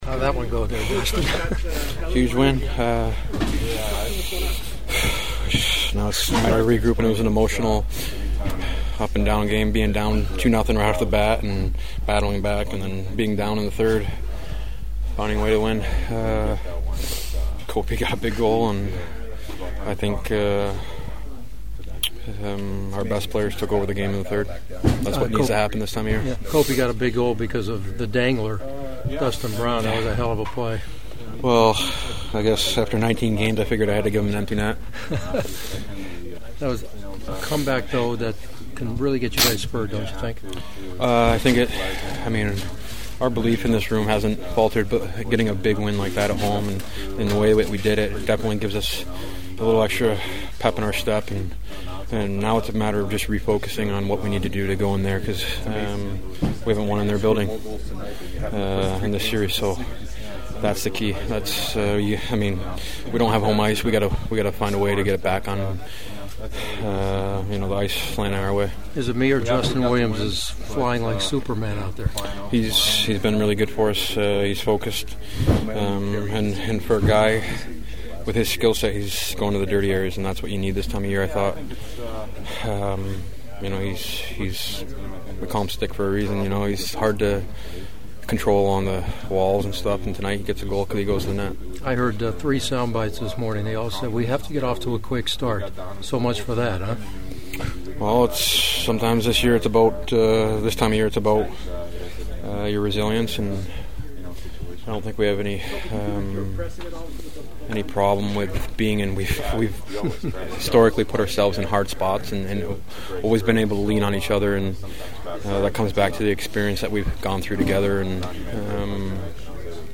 The following are my postgame chats from the locker room and they were all cautiously stoked about their chances during the rest of this series…
Kings captain Dustin Brown: